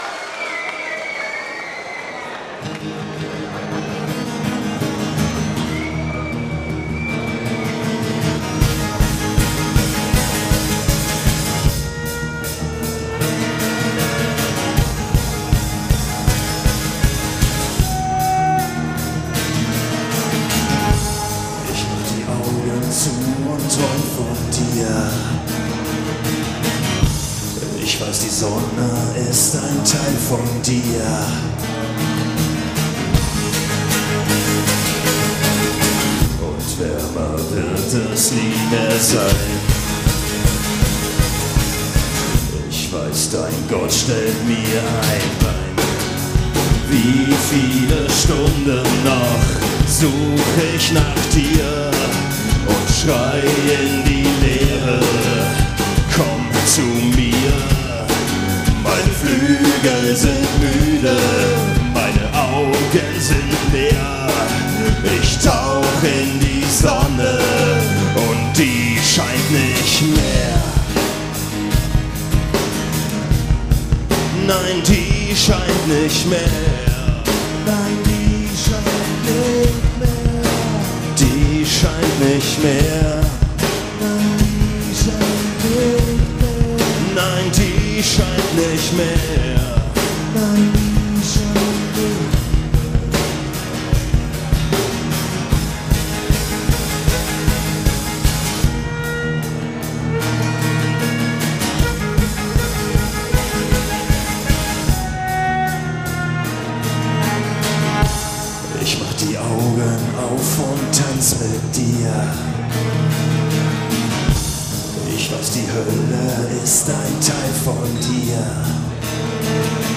unplugged & live